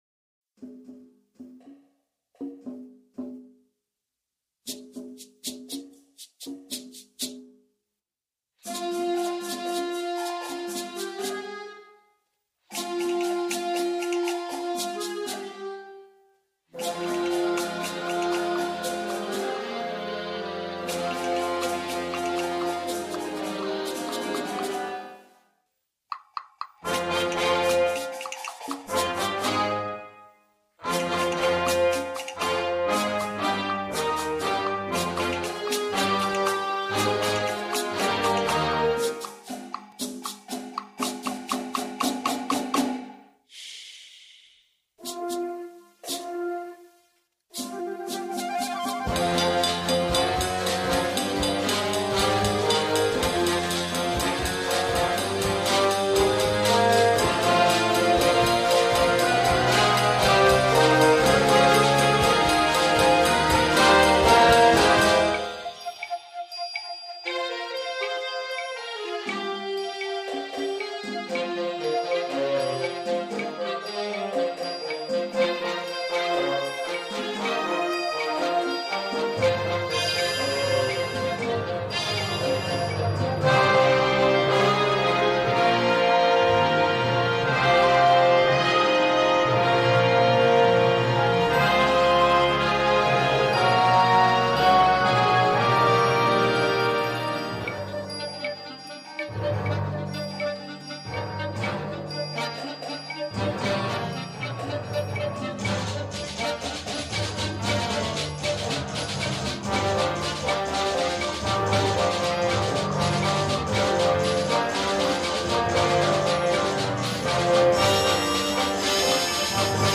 Flex Band